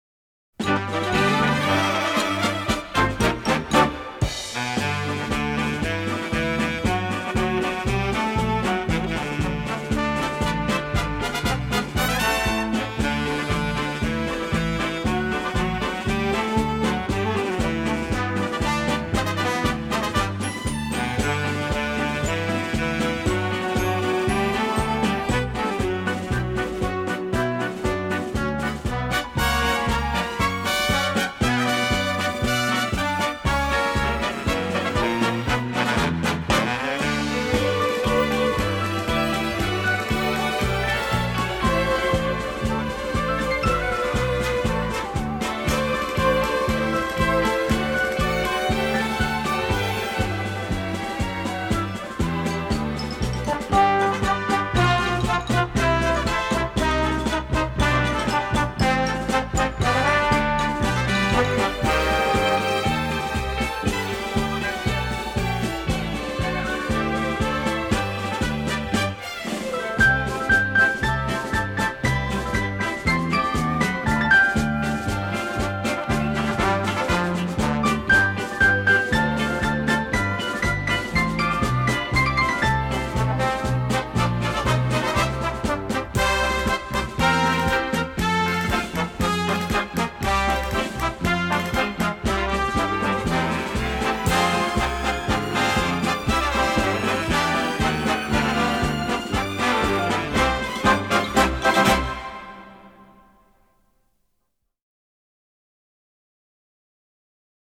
パソドブレの音楽 ３曲